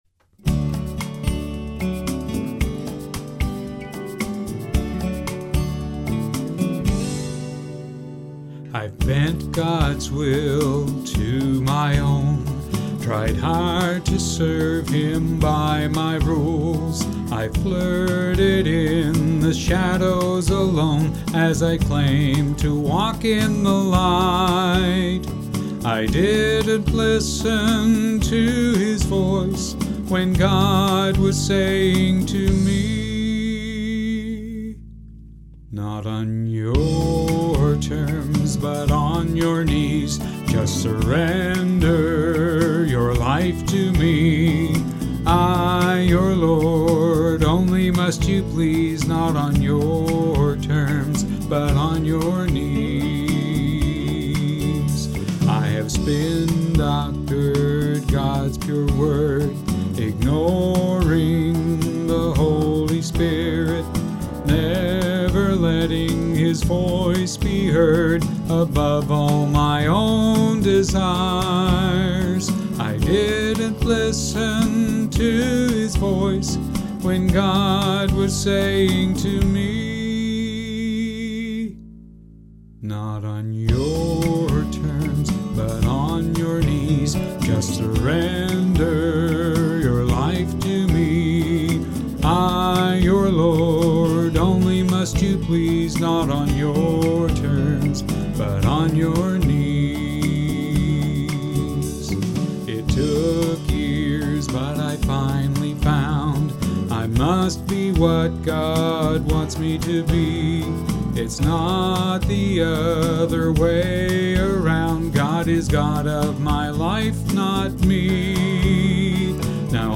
Key of D